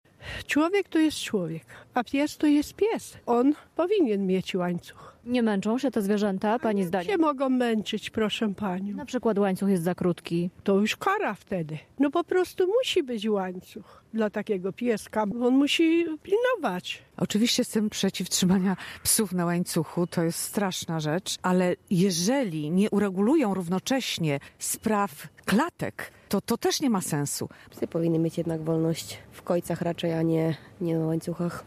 sonda.mp3